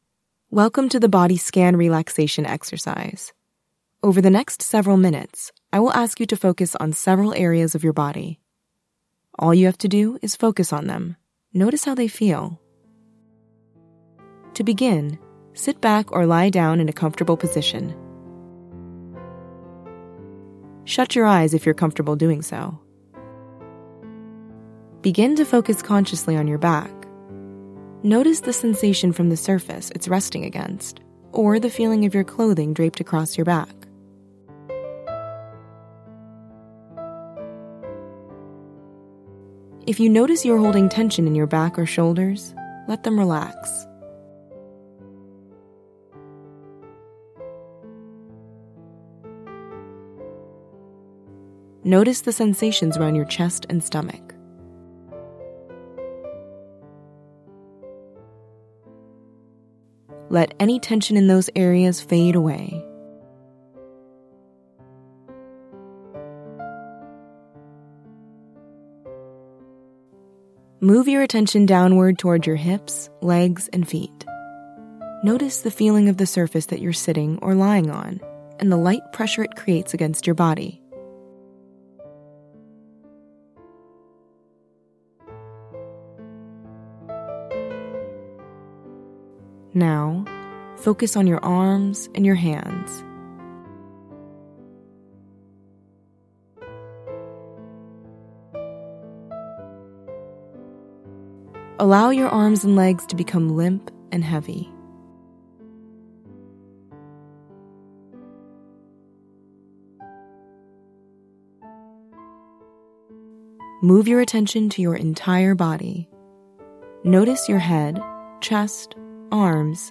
Download Guided Body Scan Audio
body-scan-female-enabled-normal.mp3